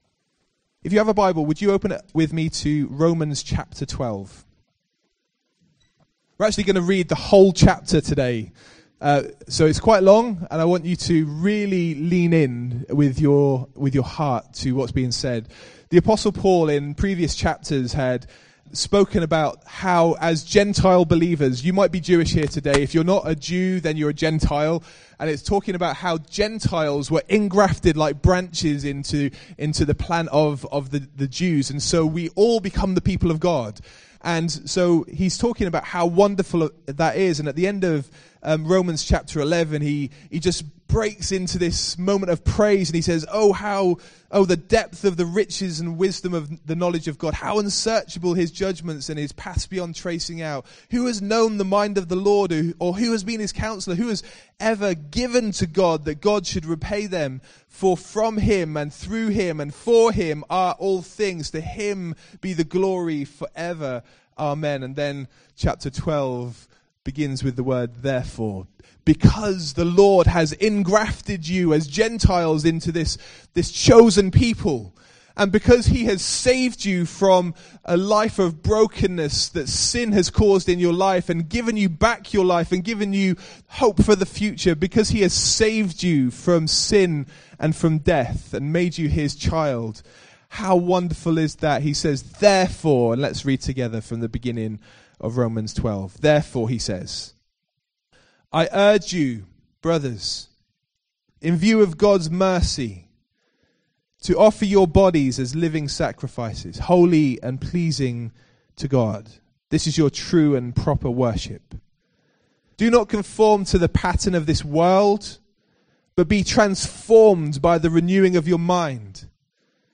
preaches an uncompromising message from Romans 12